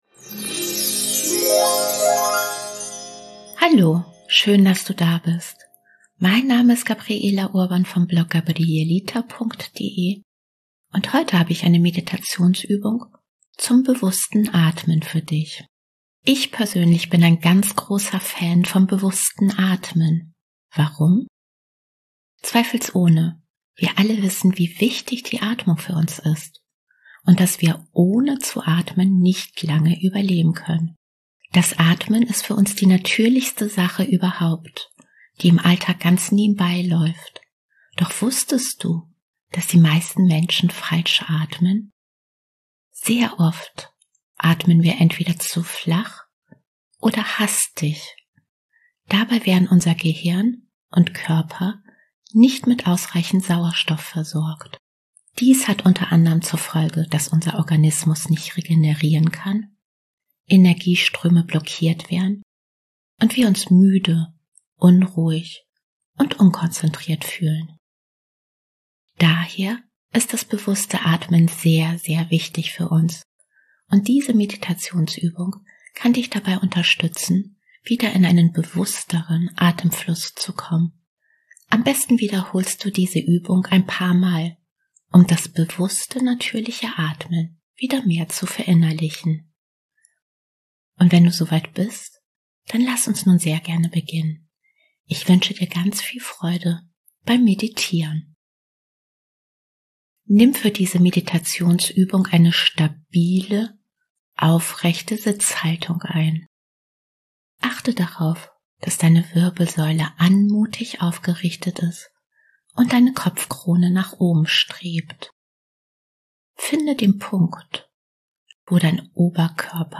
Beschreibung vor 3 Jahren Hallo herzlich willkommen zu meinem Podcast Traumreisen und geführte Meditationen.